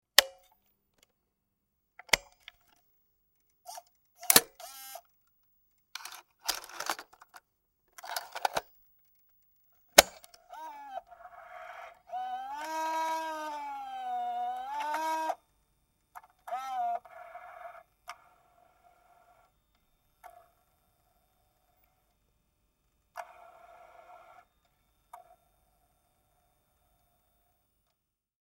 SVHS Camcorder Panasonic Movie Camera NV-S88